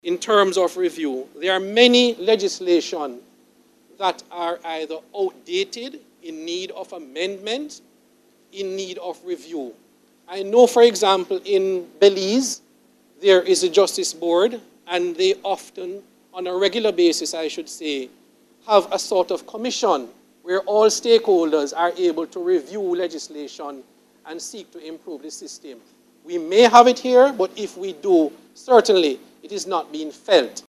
He was speaking on behalf of the private bar, at this morning's opening of the hilary term of the Home Circuit Court.